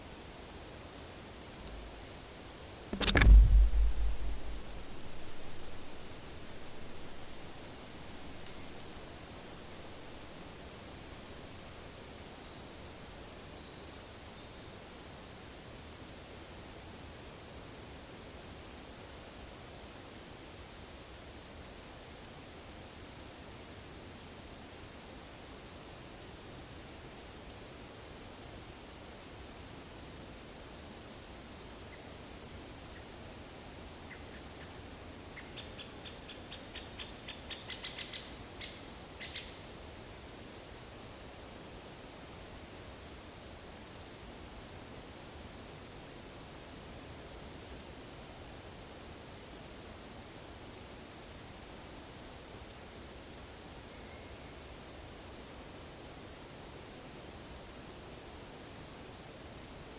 Monitor PAM
Certhia familiaris
Leiopicus medius
Turdus iliacus
Certhia brachydactyla